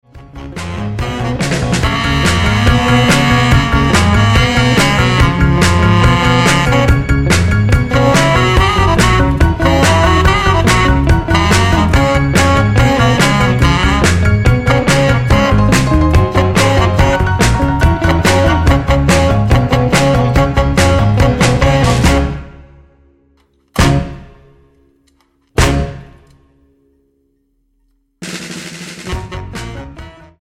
Tonart:C# ohne Chor
Die besten Playbacks Instrumentals und Karaoke Versionen .